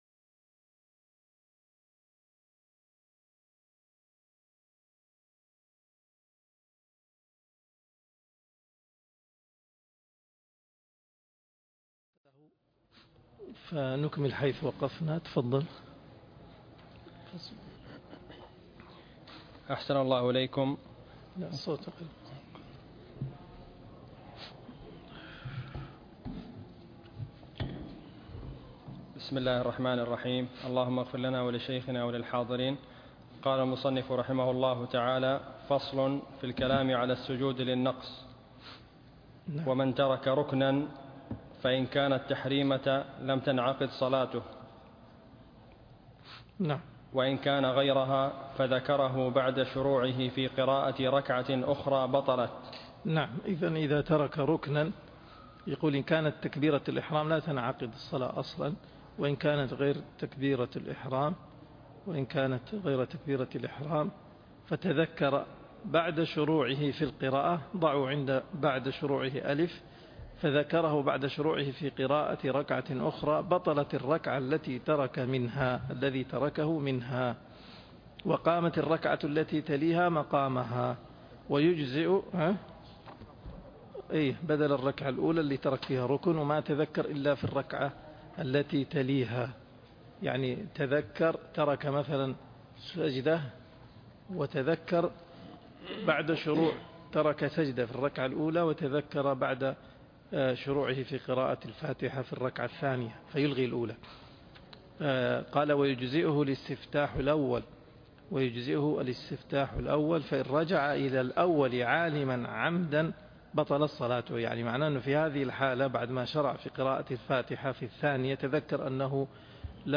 الدرس 37 (شرح الروض المربع